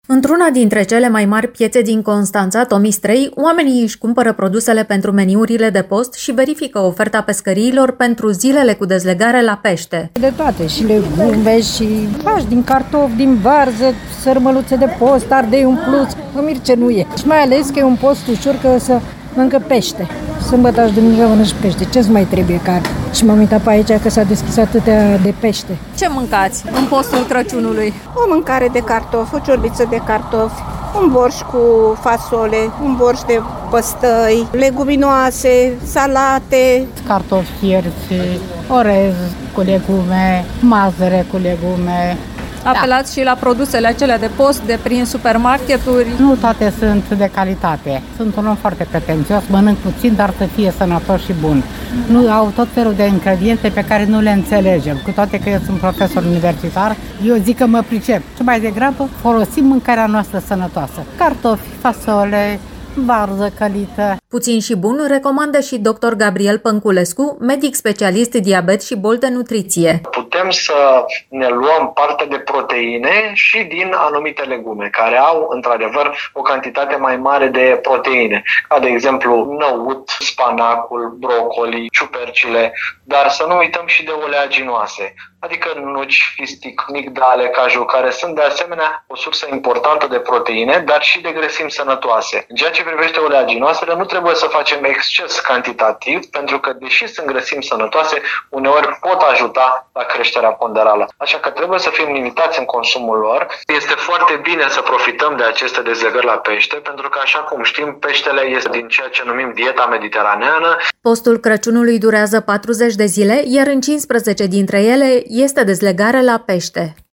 Specialiștii le recomandă celor care țin post să profite și de numărul mare de zile cu dezlegare la pește. Cum s-au pregătit pentru post constănțenii, aflați din reportajul următor.